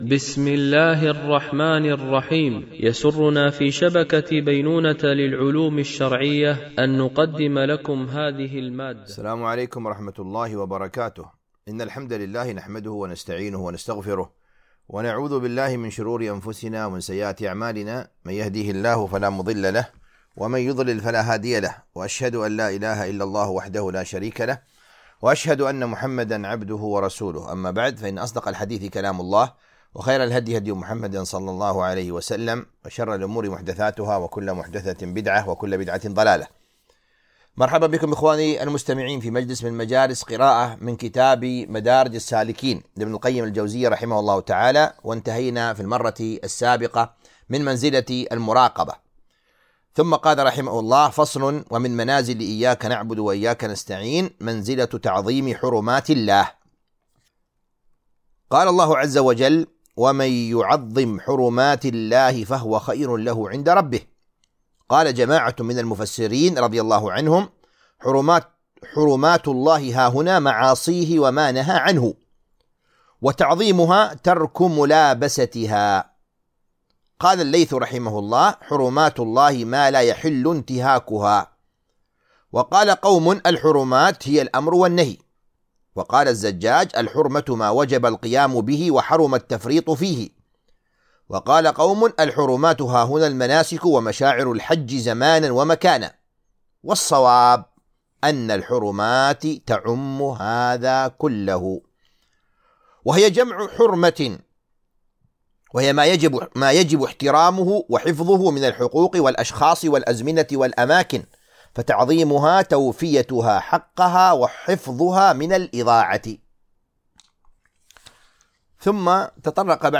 قراءة من كتاب مدارج السالكين - الدرس 62